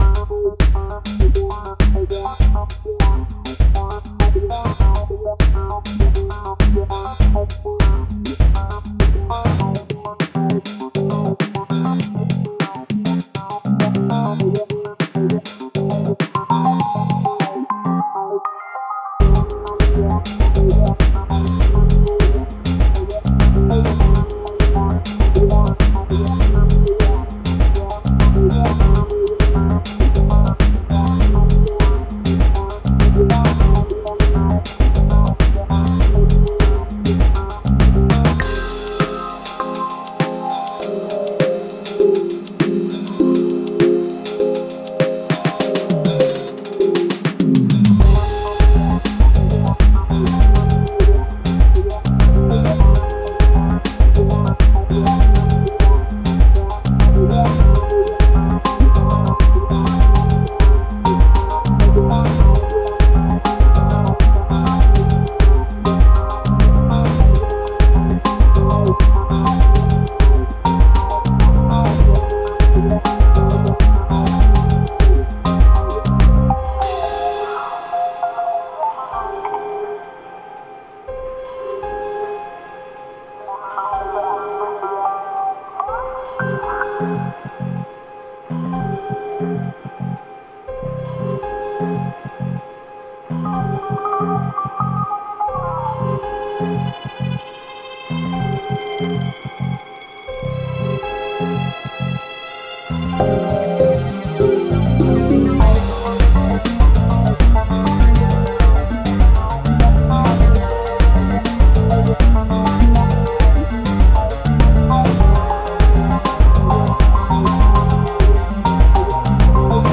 These "songs" are just the results of me playing with Renoise. I usually drop some sounds to the timeline and listen to them looped.